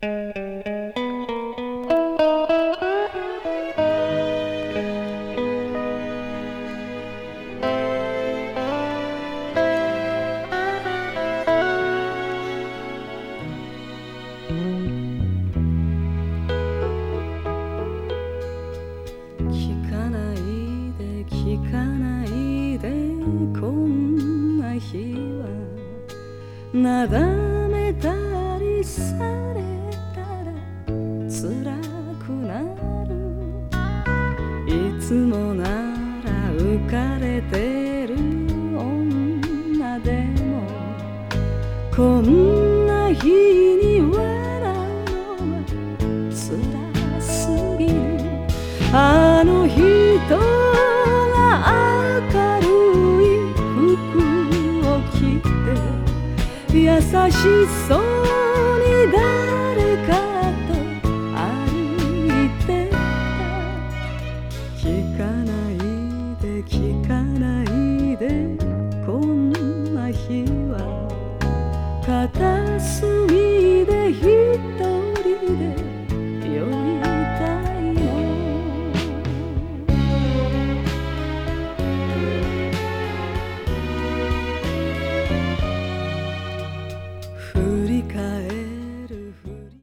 a bluesy, impassioned performance